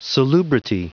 Prononciation du mot salubrity en anglais (fichier audio)
Prononciation du mot : salubrity
salubrity.wav